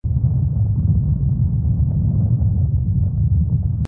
tt_s_ara_cmg_groundquake.mp3